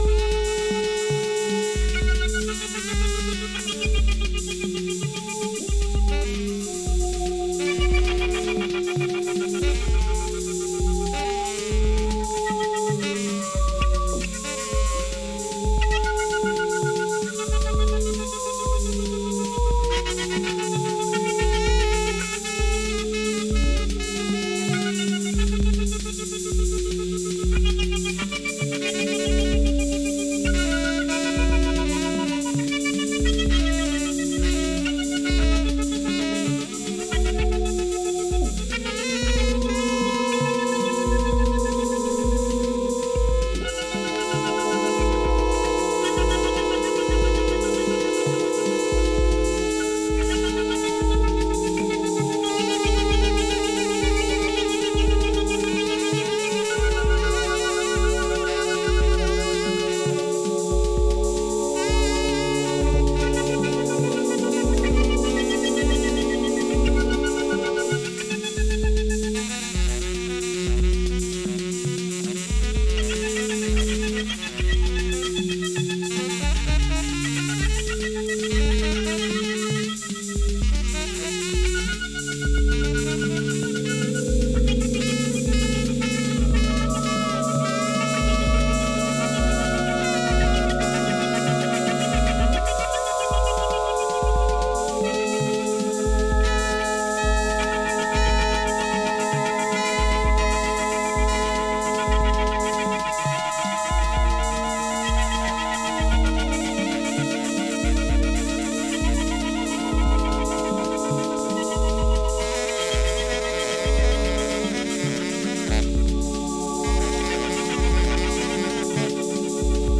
electronic instrumental improvisational music
which has slight jazz influence
Electronix Ambient